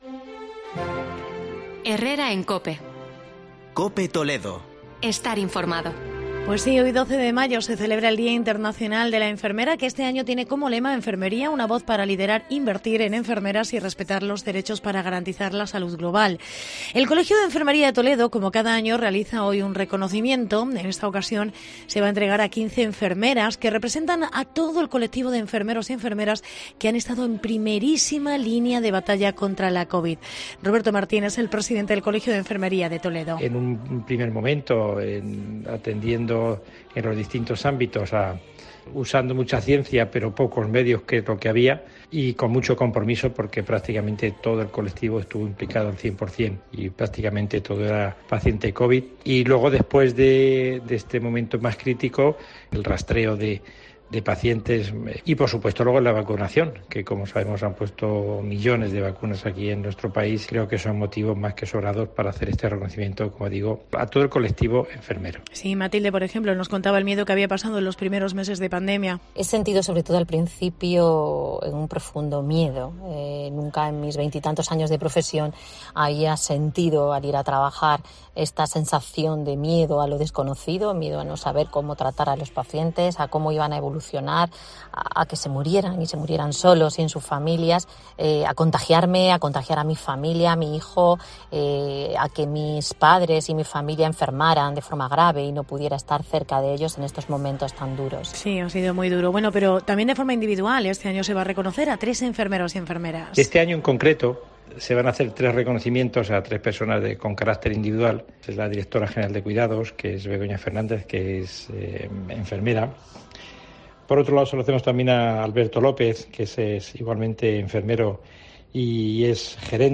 Reportaje enfermeros